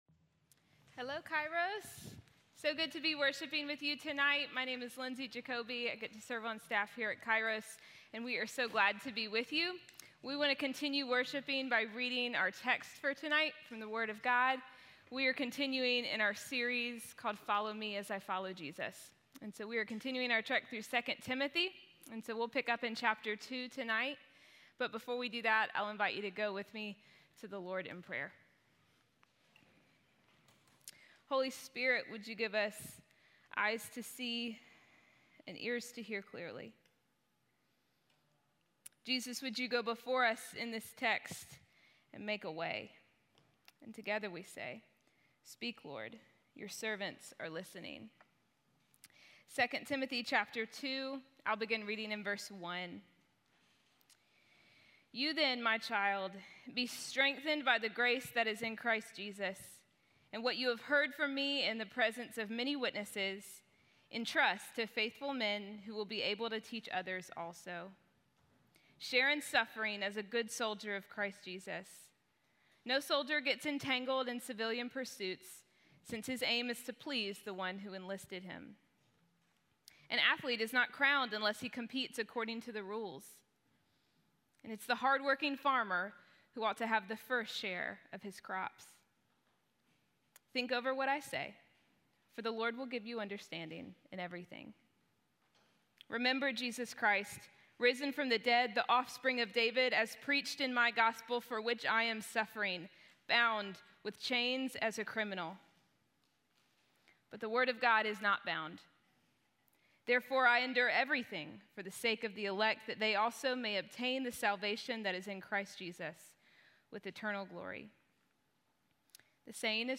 Spiritual Strength & Conditioning - Sermon - Kairos Nashville